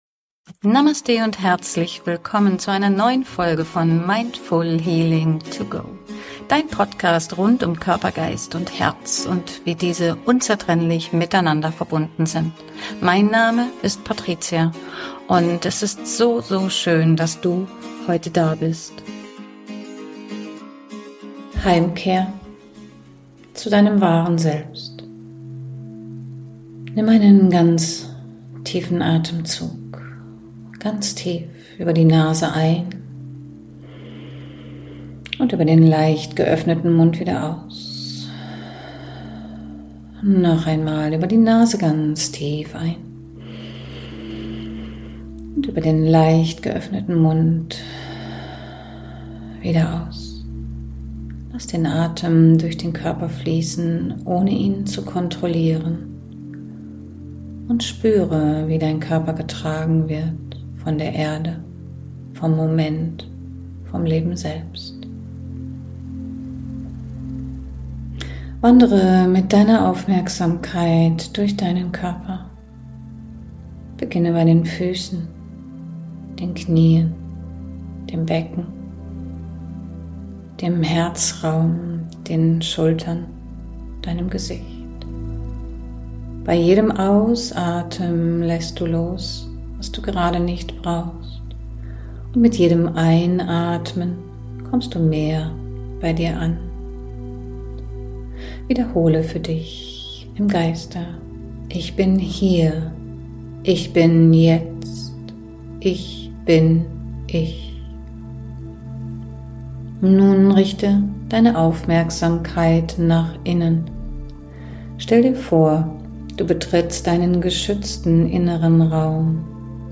Meditation: Heimkehr zum wahren Ich